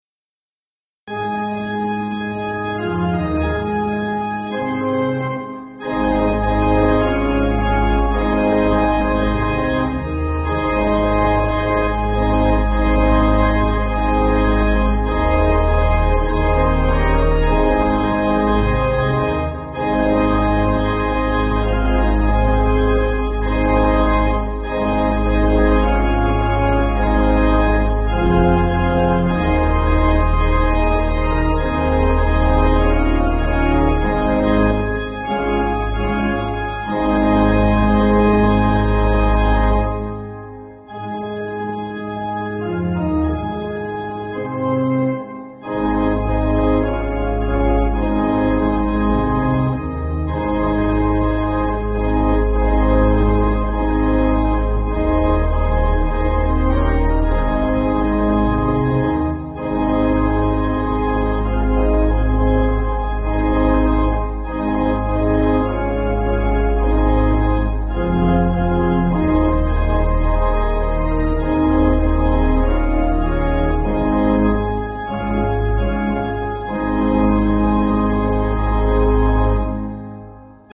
Organ
(CM)   6/Ab